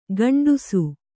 ♪ gaṇḍusu